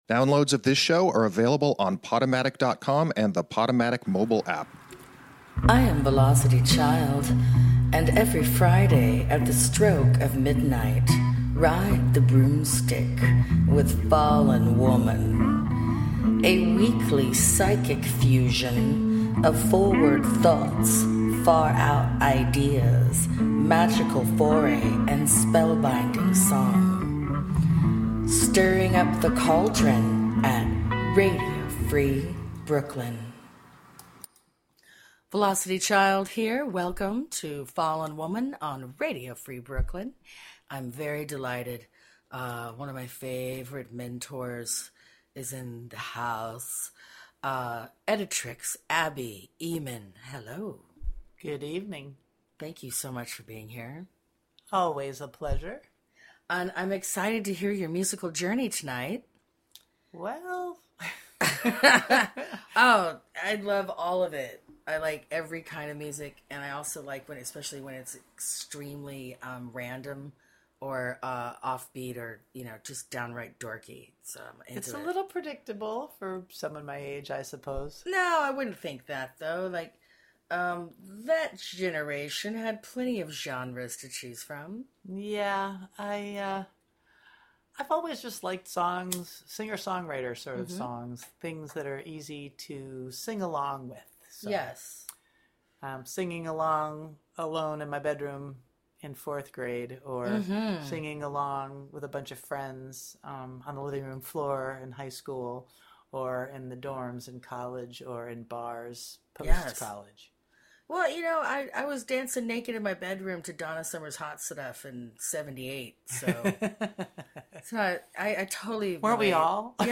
Take this oh so groovy slide down her memory lane with music that shaped her and why. This shamanistic hippie hour is one peaceful yet potent vibe! Welcome to Fallen Woman!